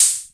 Index of /musicradar/essential-drumkit-samples/Vintage Drumbox Kit
Vintage Open Hat 01.wav